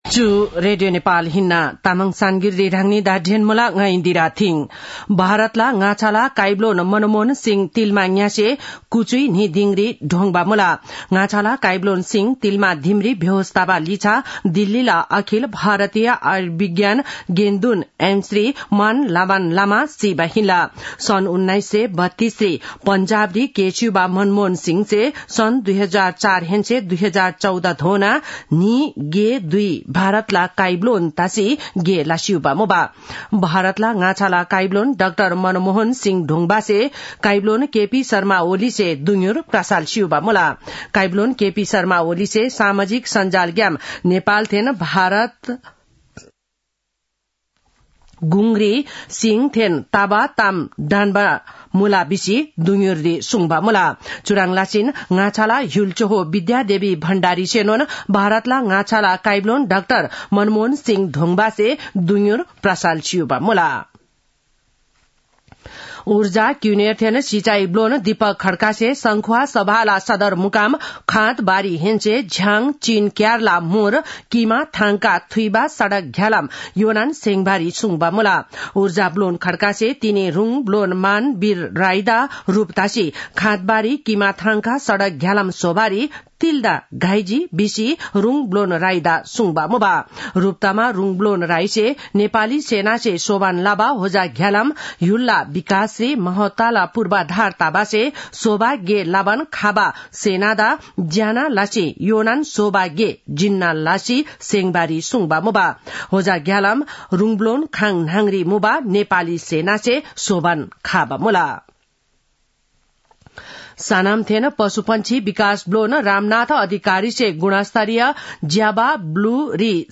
तामाङ भाषाको समाचार : १३ पुष , २०८१